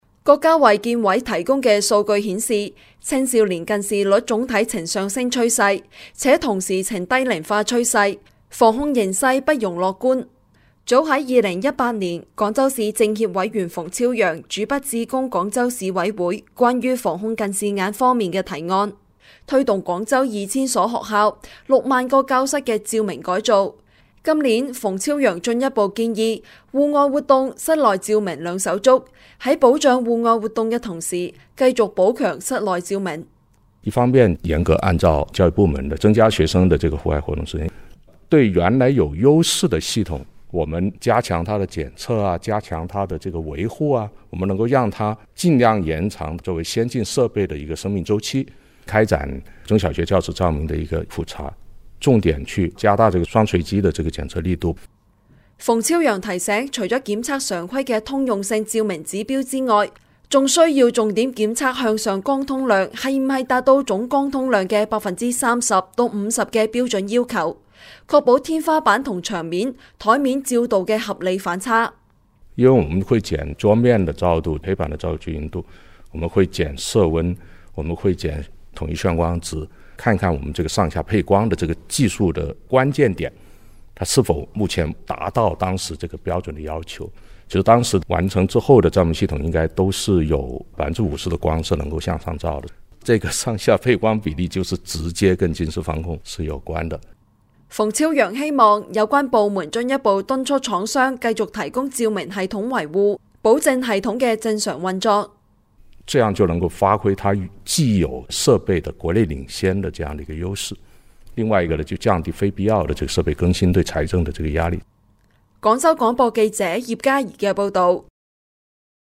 音频来源：广州市广播电视台新闻资讯广播
广州广播记者报道.mp3